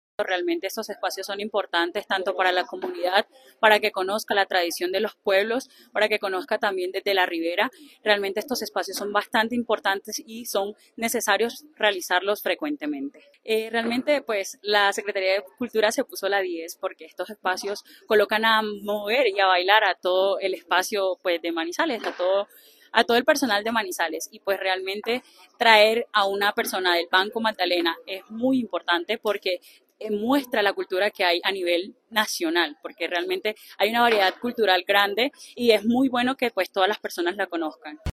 Asistente al concierto